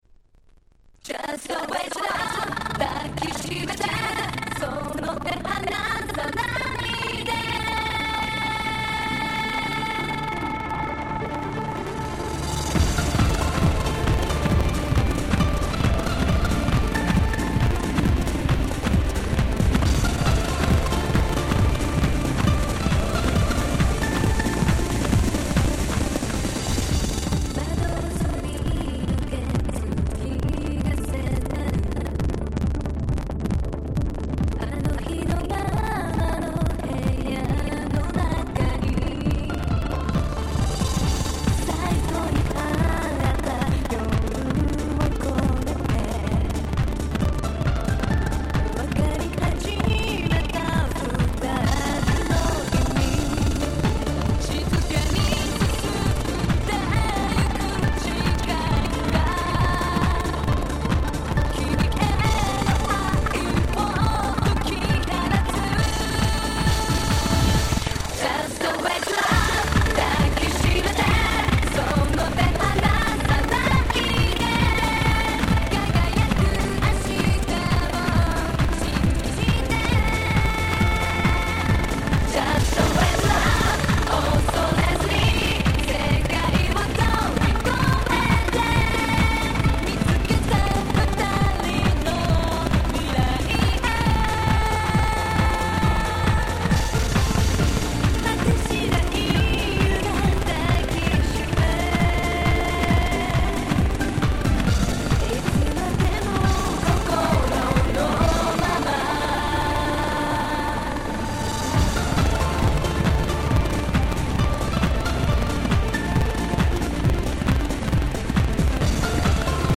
03' Nice Japanese R&B !!